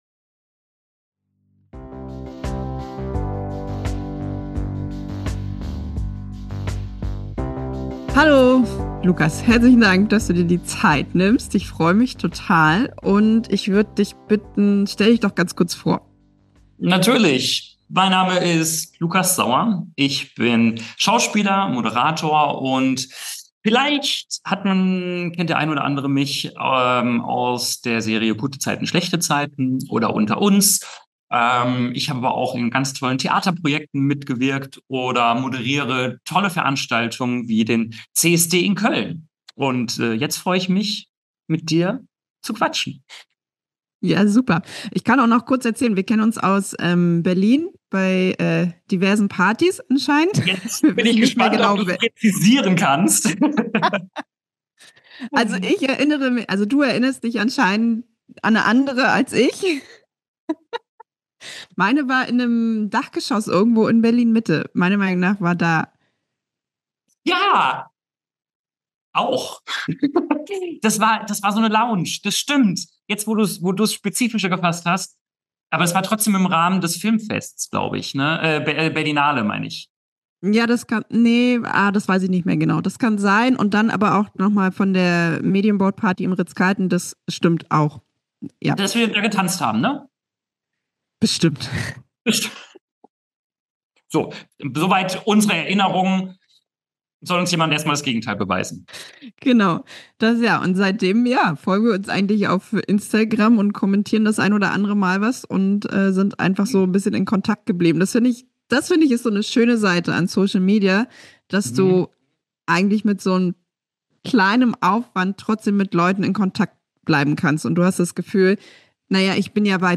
spricht mit mir über seinen Heiratsantrag, die Hochzeitsplanung und die Frage, wie sich eine Beziehung verändert, wenn aus Liebe Ehe wird. Wir reden über Freudentränen, Stressmomente und das, was wirklich zählt: Bewusstsein für die Liebe.